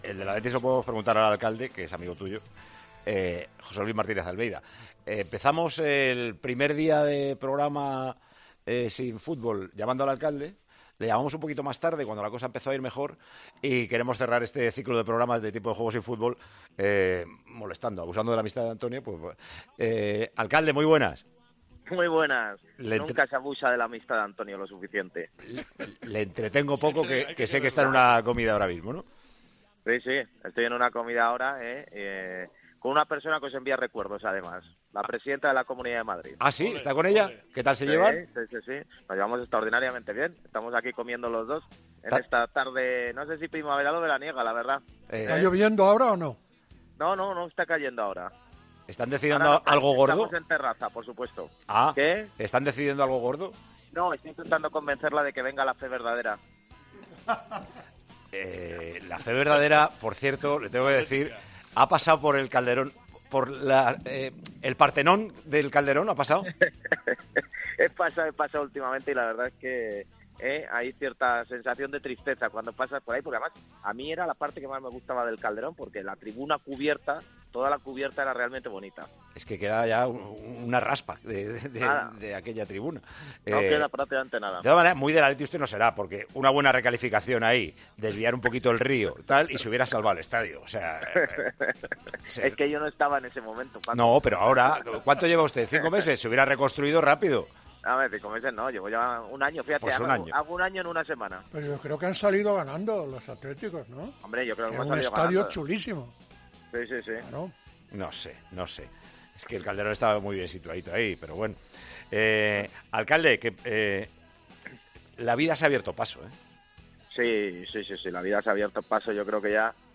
El alcalde de Madrid y la presidenta de la Comunidad, juntos en Tiempo de Juego